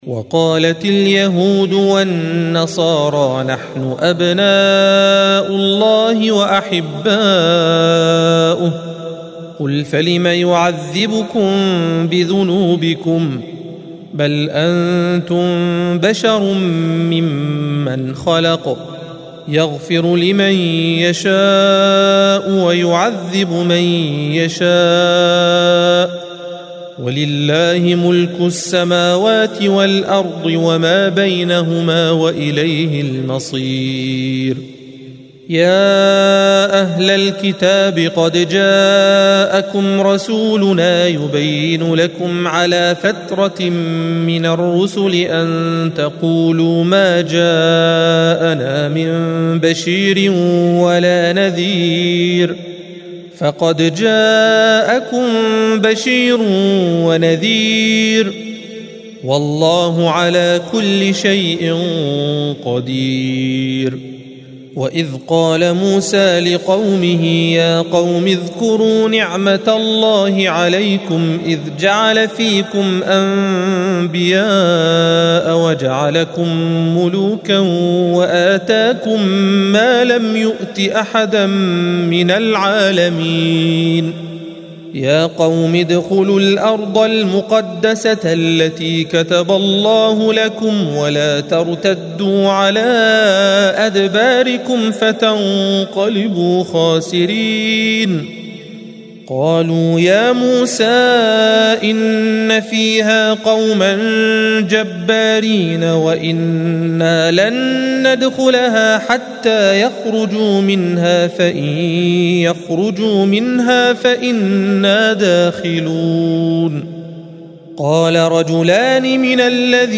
الصفحة 111 - القارئ